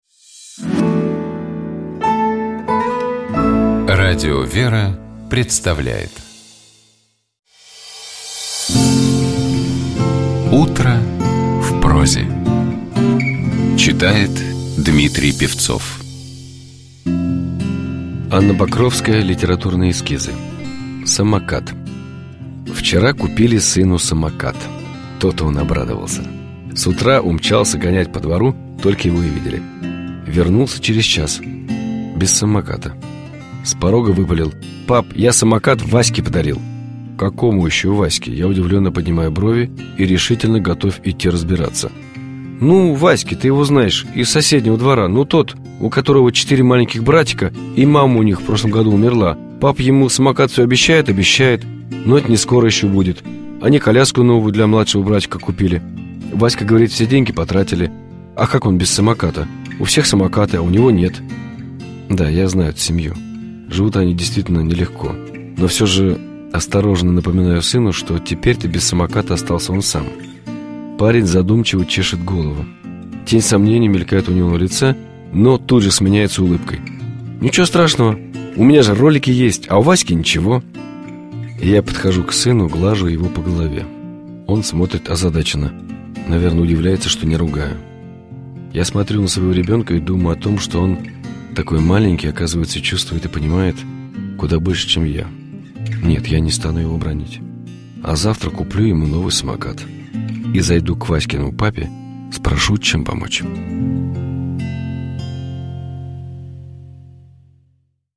Текст Анны Покровской читает Дмитрий Певцов.